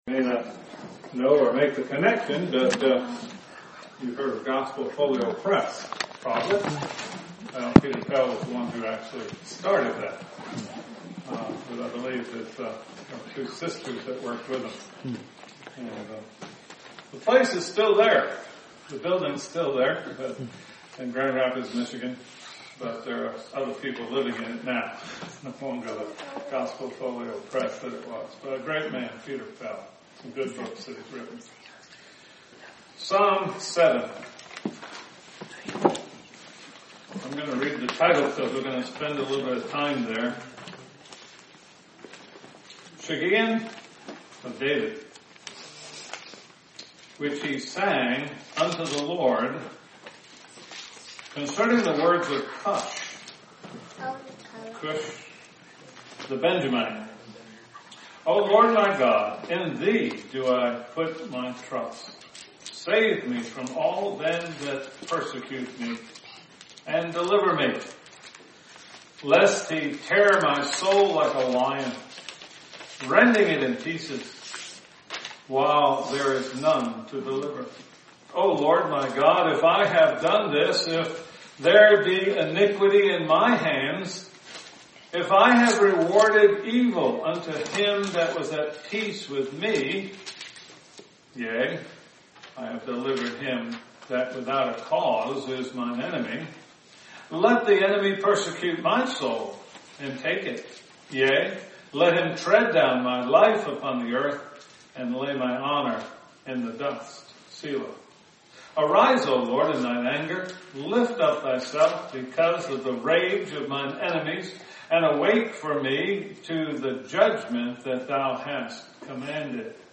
Psalm 7 Service Type: Sunday School Bible Text